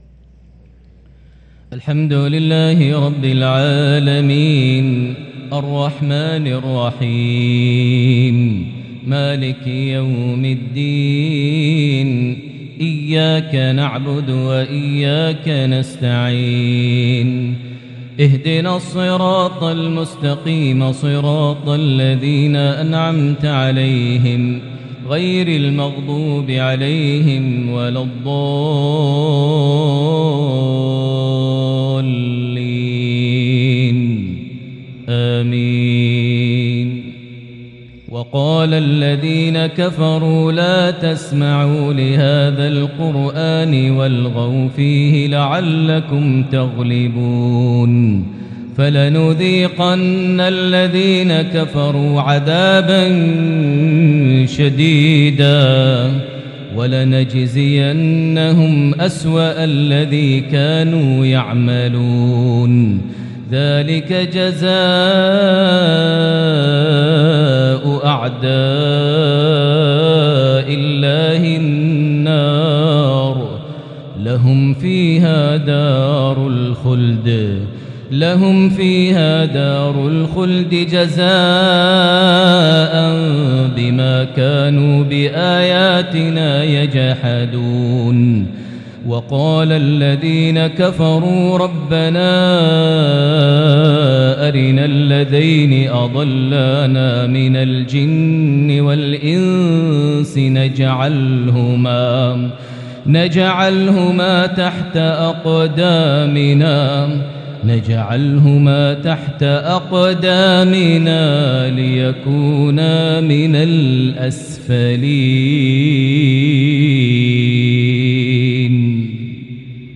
صلاة المغرب من سورة فصلت 26-35 | 30 رجب 1443هـ| maghrib 3-3-2022 prayer from Surah Fussilat 26-35 > 1443 🕋 > الفروض - تلاوات الحرمين